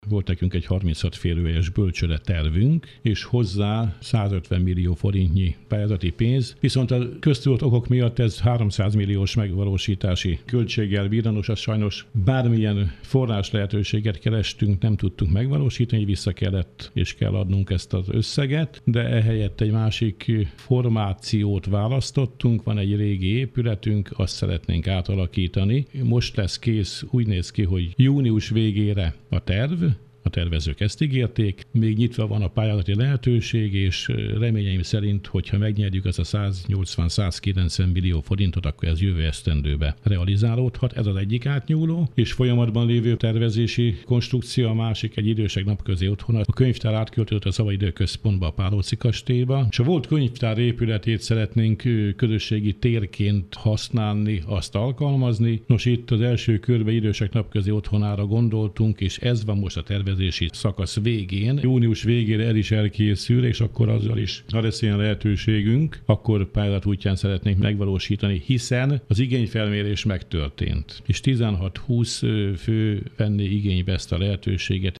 Hasonló módon alakítanák ki az idősek napközi otthonát a könyvtár egykori épületéből - mondta Kulcsár István polgármester.